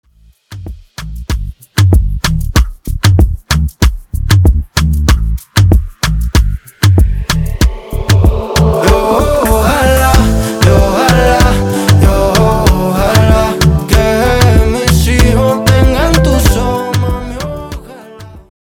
Coro Dirty